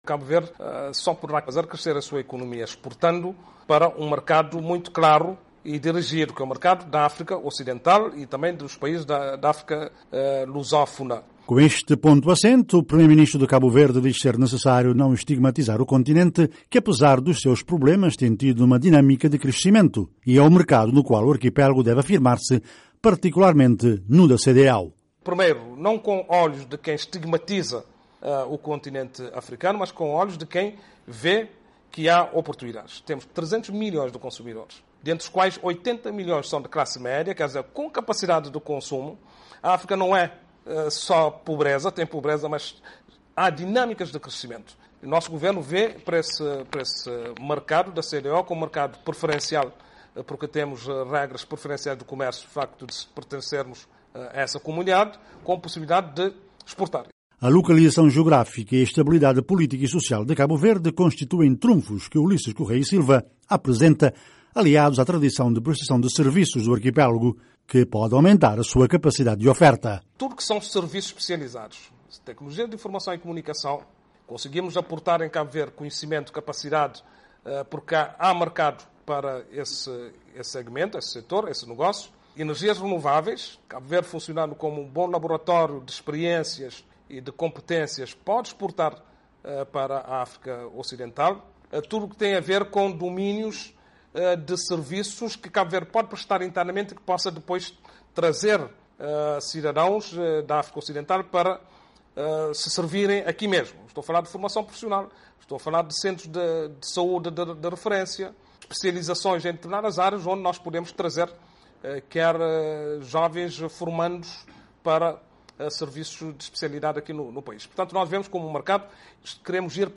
Em entrevista à VOA na cidade da Praia, Ulisses Correia e Silva lembra que Cabo Verde não pode fazer depender a sua economia do turismo.